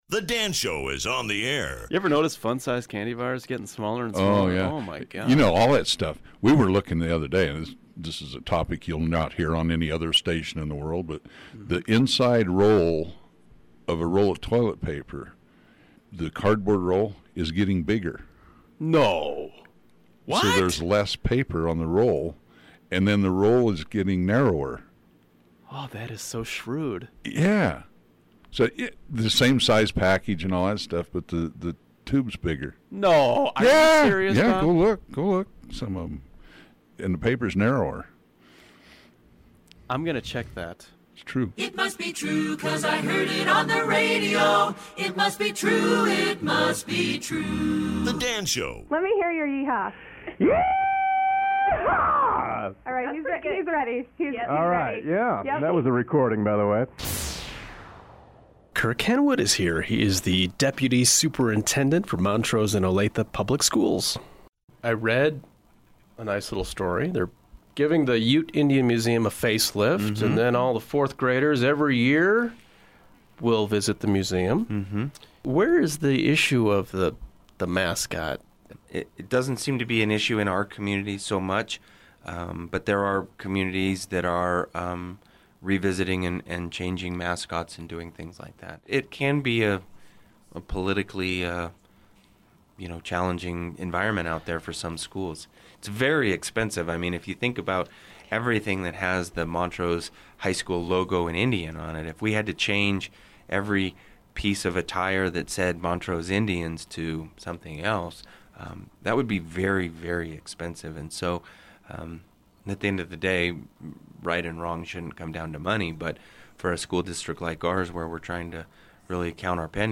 Here's a sample of my current morning talk show.